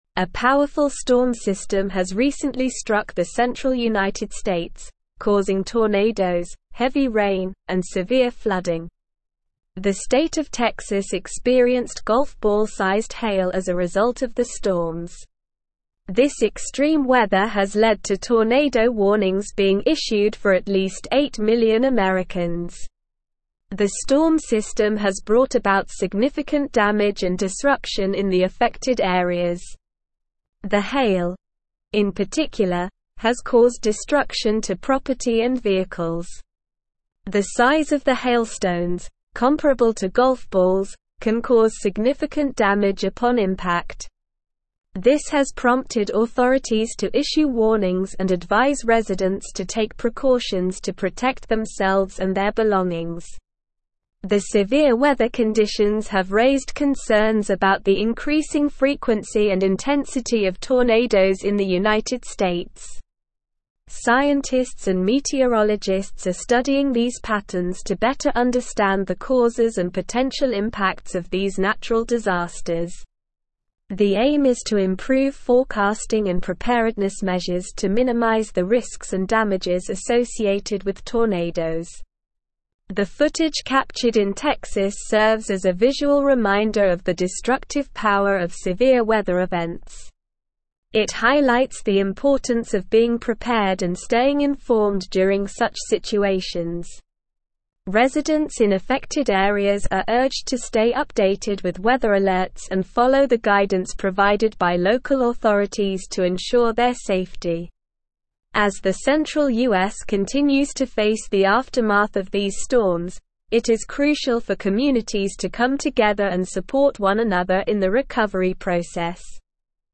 Slow
English-Newsroom-Advanced-SLOW-Reading-Severe-Storm-System-Causes-Tornadoes-Flooding-and-Hail.mp3